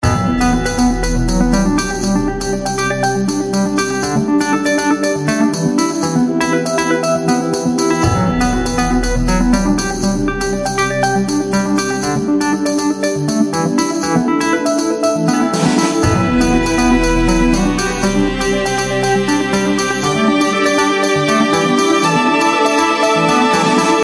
史诗般地建立循环
描述：用vst乐器创造了另一个“无尽的”循环。用电钢琴开始制作快速铃声，最后加入大提琴，打击乐器和唱诗班乐趣，希望你喜欢它！
Tag: 循环 拖车 史诗 好莱坞 英雄 介绍 铃声 管弦乐 兴隆